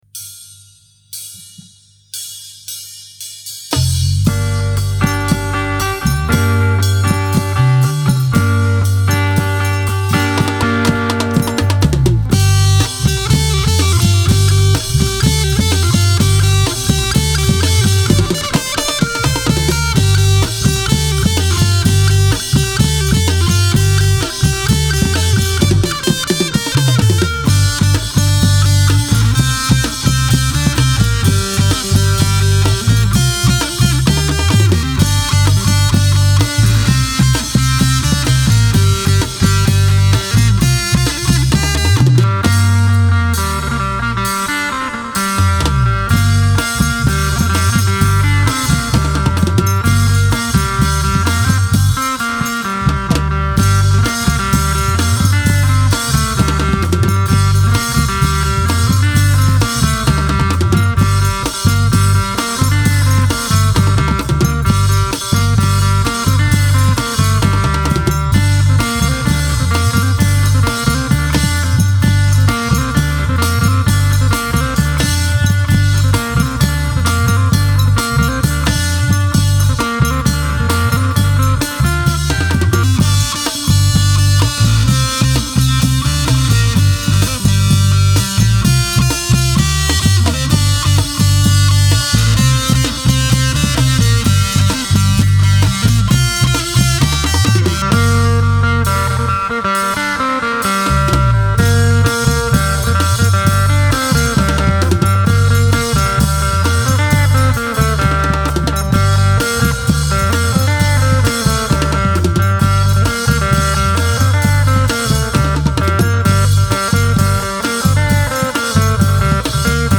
Genre: World, Tai